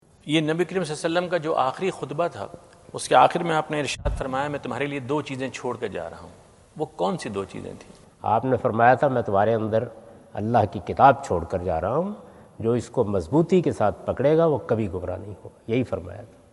Javed Ahmad Ghamidi answer the question about "In the last sermon of prophet (PBUH) what are two major things he discussed?" During his US visit at Wentz Concert Hall, Chicago on September 23,2017.